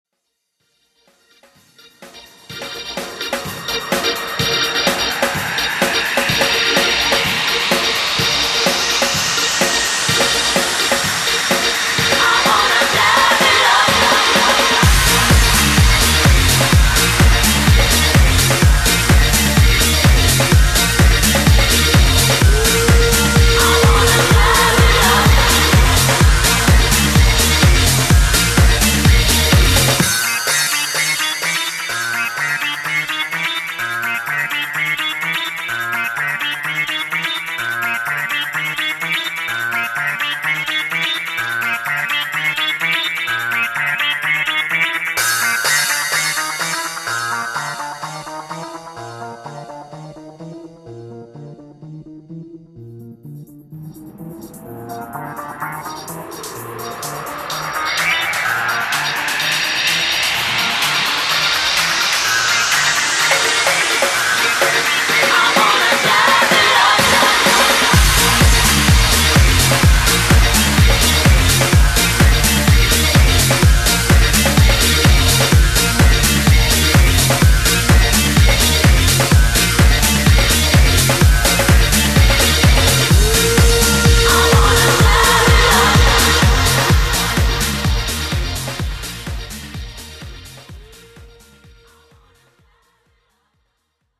Жанр: Club • Dance • DJs